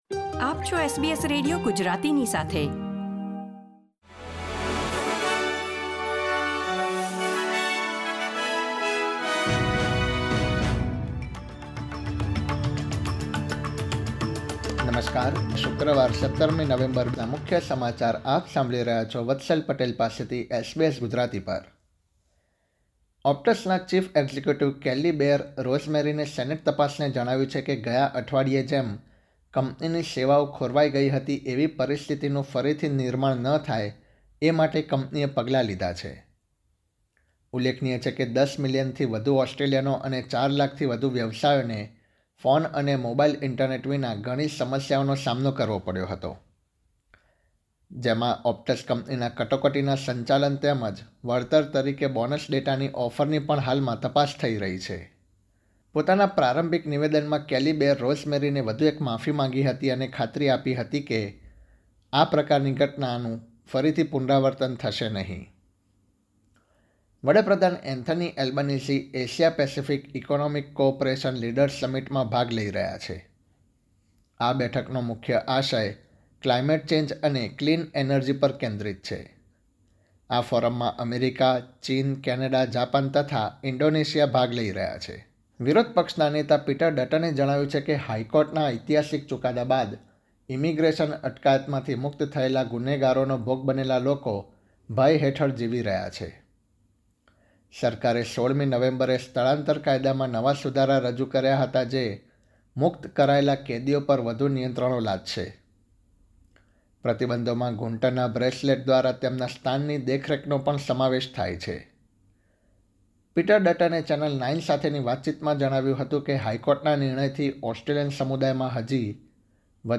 SBS Gujarati News Bulletin 17 November 2023